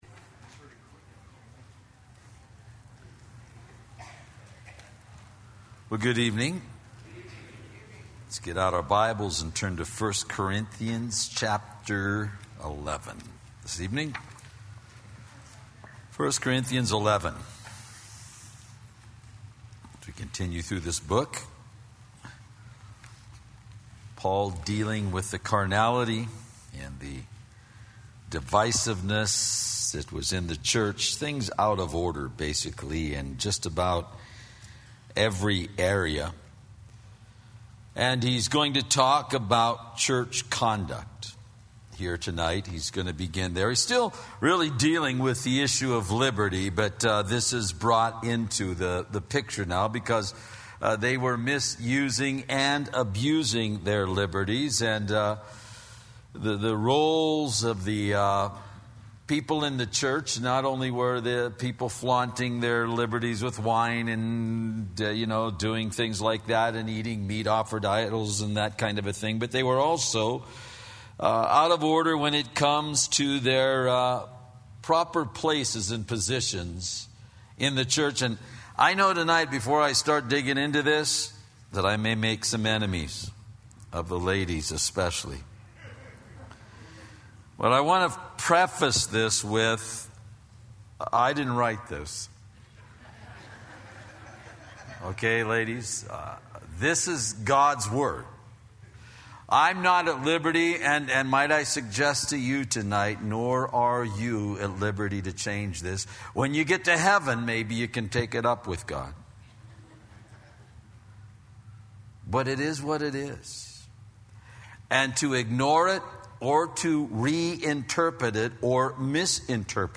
Sermon Details Calvary Chapel High Desert